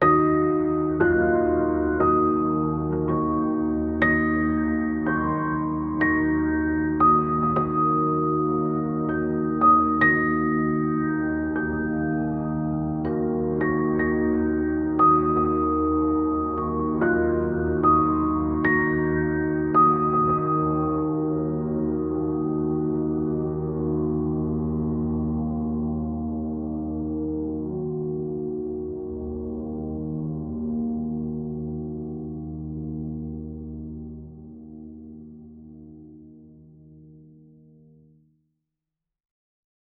Dark piano bell melody in D# minor